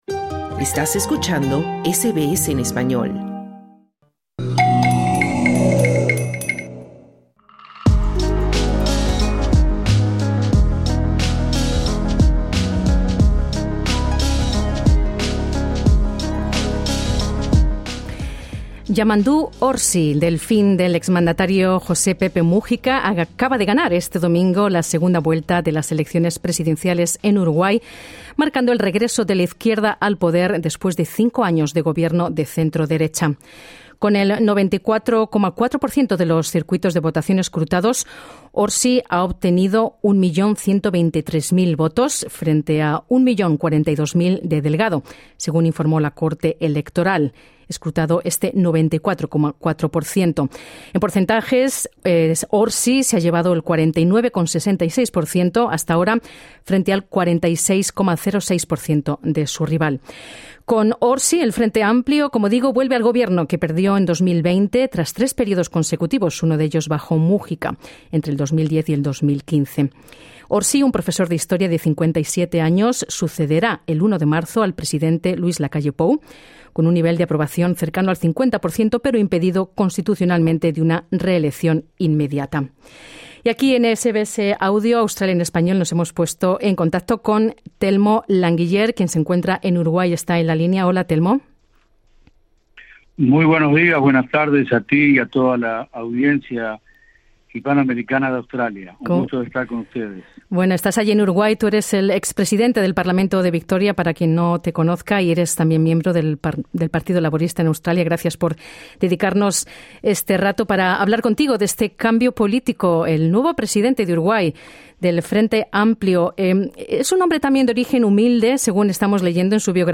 El expresidente del Parlamento de Victoria, Telmo Languiller, comparte desde Montevideo con SBS Spanish sus impresiones ante este cambio de gobierno en su país.